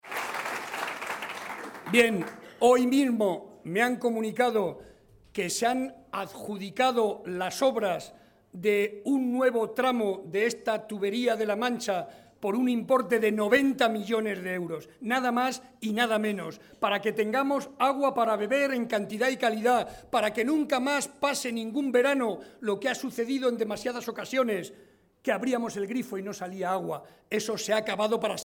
Barreda hacía estas declaraciones ante más de 400 vecinos de la localidad que aplaudieron entusiasmados este anunció y reconocieron el trabajo del jefe del ejecutivo autonómico a lo largo de estos años con palabras de ¡presidente, presidente!.